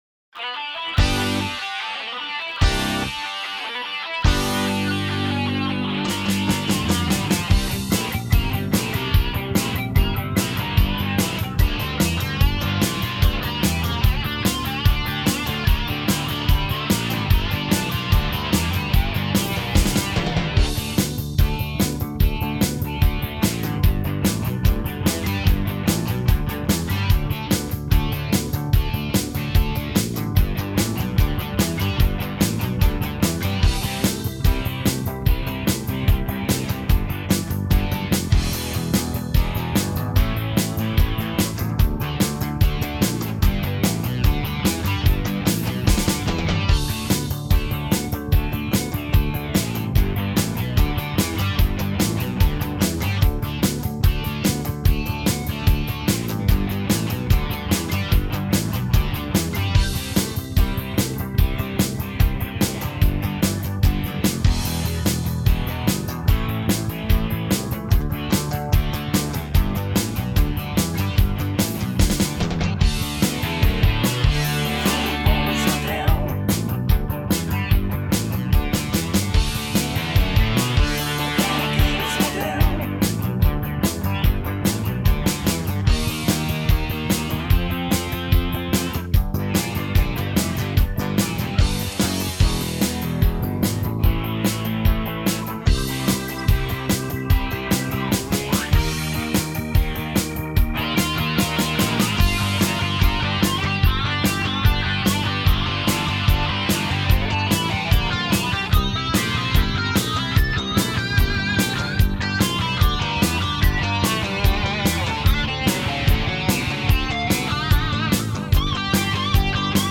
минусовка версия 3289